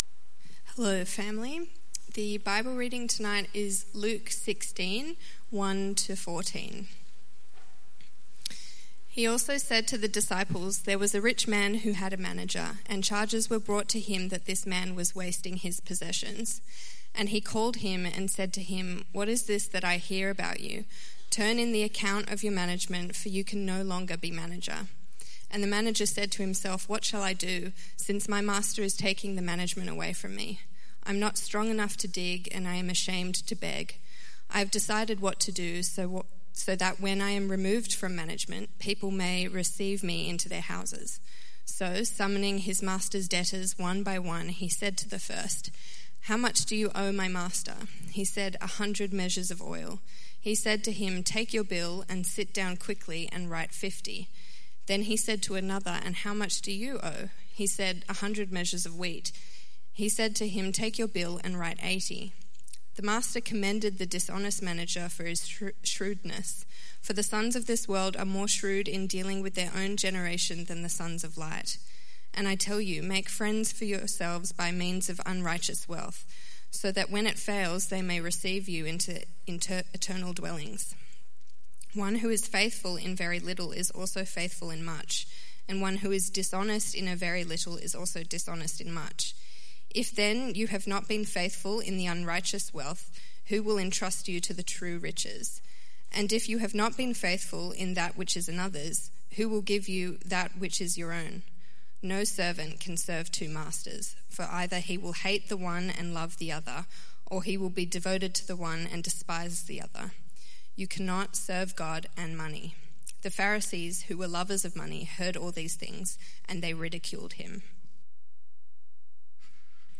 Preacher
Service Type: 6.30PM